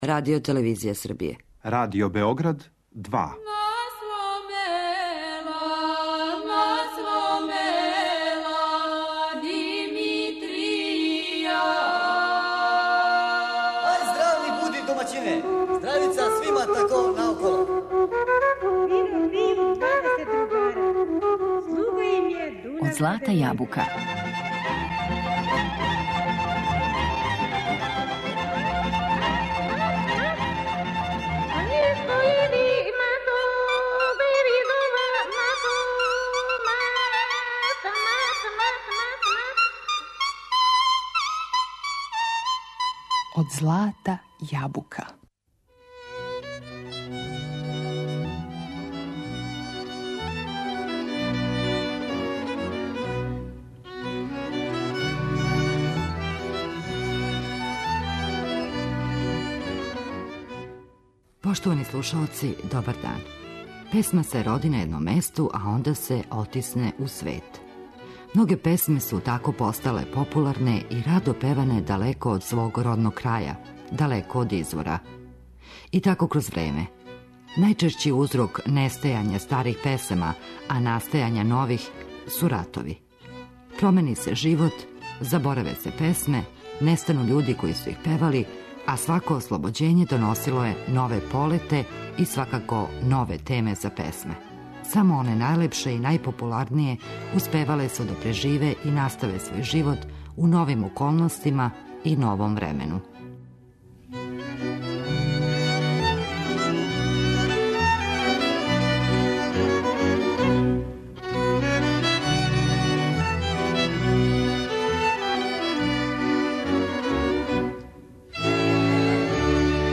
Данашњу емисију смо посветили старим, лепим и скоро заборављеним песмама, које представљају праве бисере наше пребогате музичке традиције.